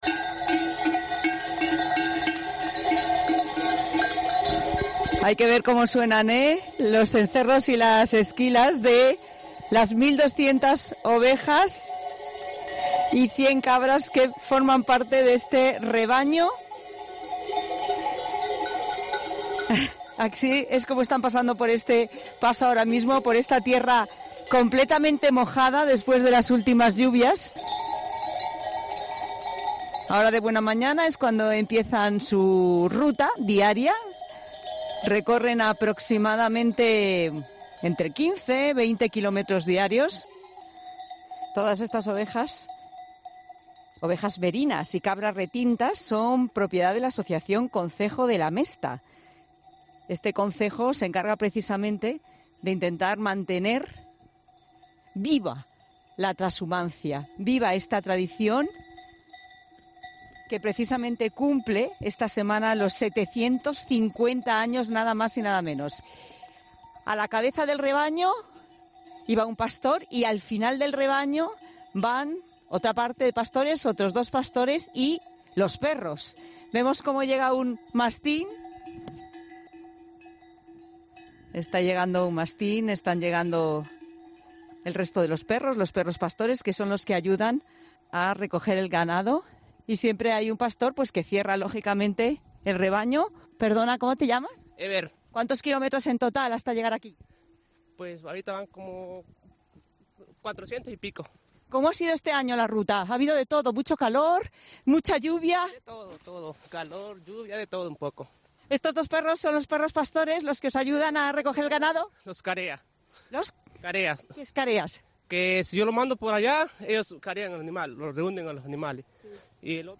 Hay que ver como suenan. Los cencerros de las 1.200 ovejas y 100 cabras que forman parte de este rebaño.
La transhumancia, una tradición que reivindica la importancia de la ganadería extensiva en España En 'La Tarde' nos hemos ido a una zona que se encuentra entre Boadilla del Monte y Pozuelo de Alarcón para dar voz a esta tradición.